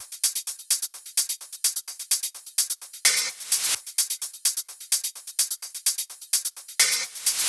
VFH2 128BPM Capone Kit 7.wav